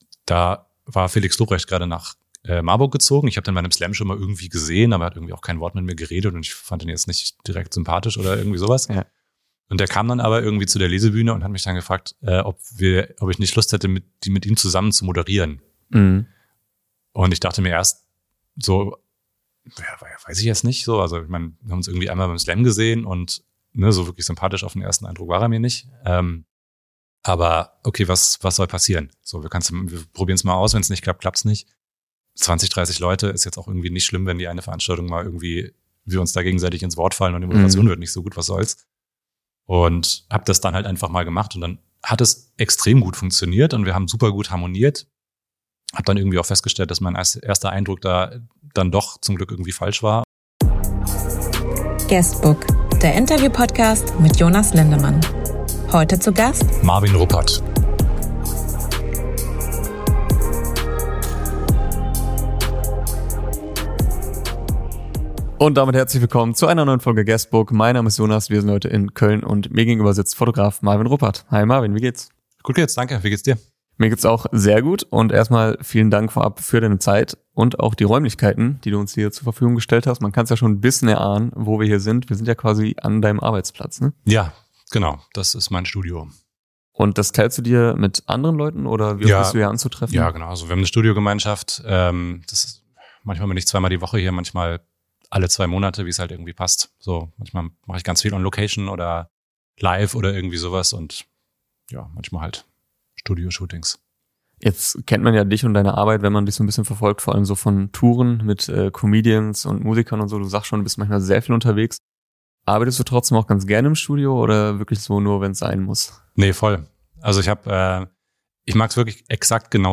Interview ~ GUESTBOOK — Der Interviewpodcast Podcast